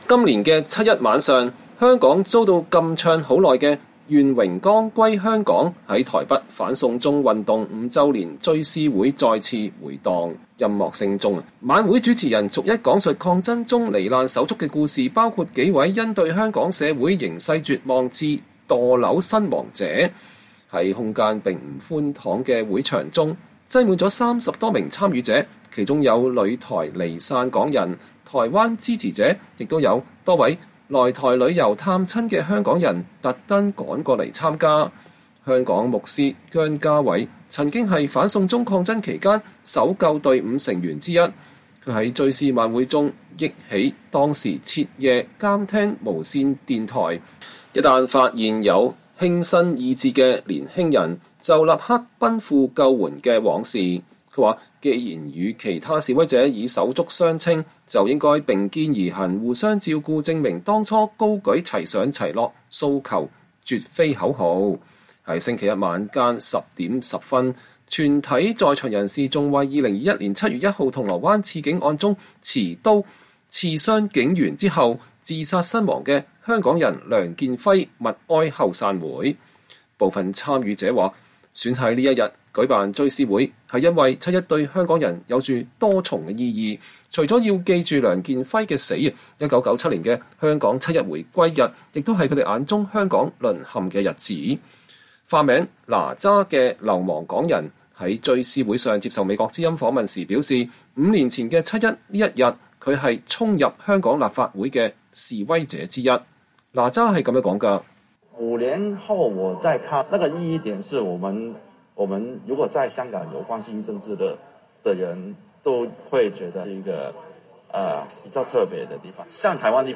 在台港人2024年7月1日在台北舉行香港反送中運動五週年追思會。